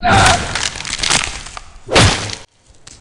Damage5.ogg